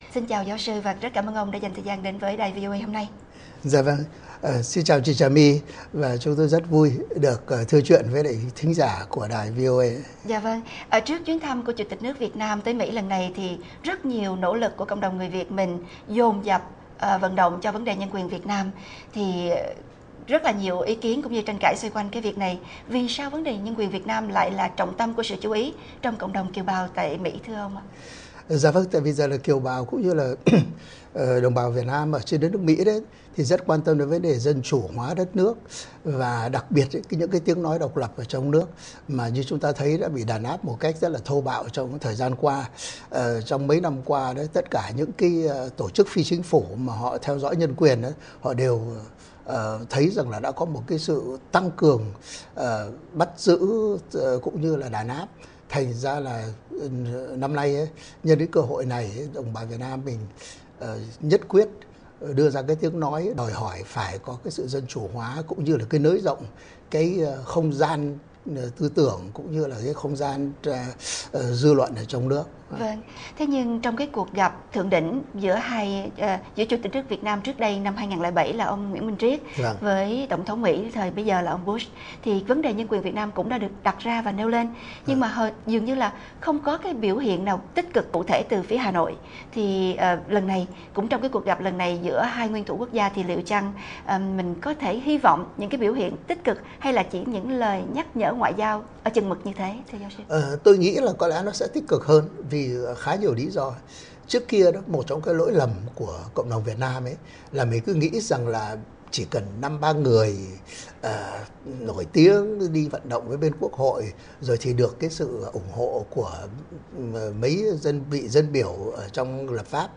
Video Phỏng Vấn